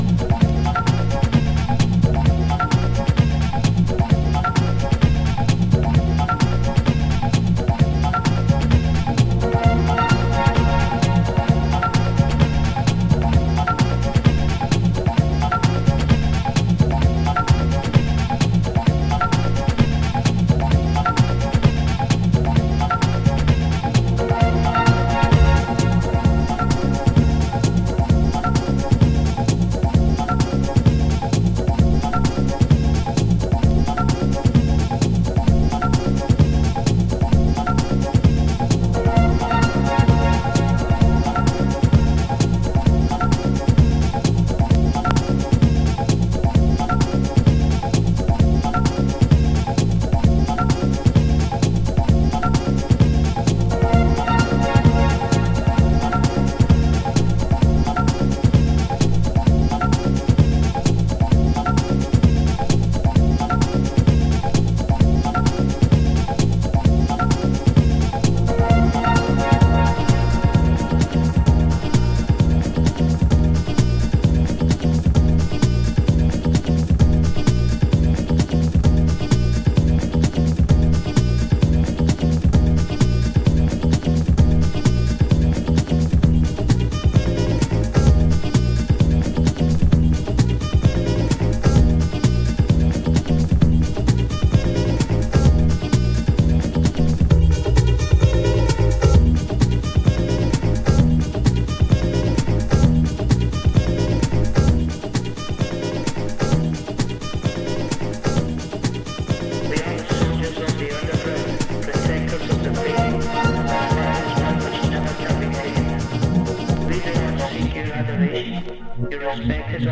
ラテンフュージョンをサンプリング・ループ、サウダージ・フィルターハウス。